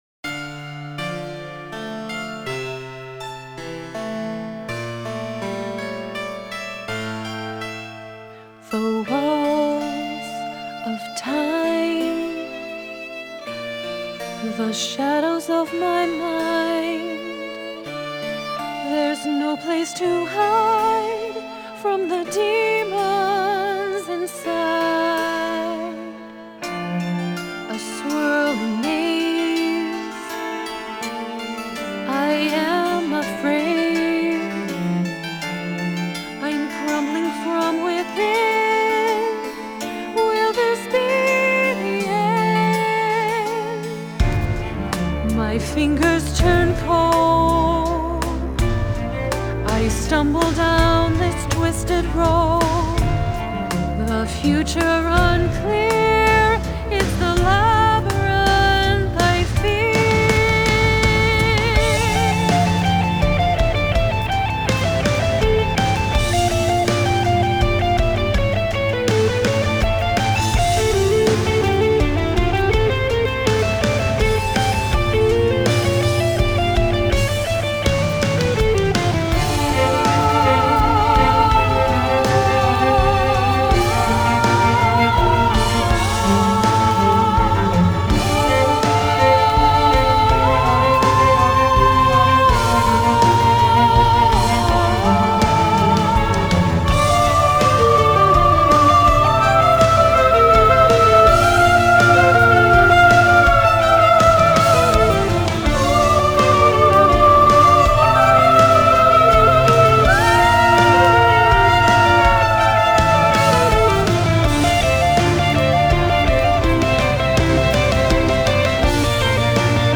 Genre: Electronic, Classical Crossover, Singer-SAongwriter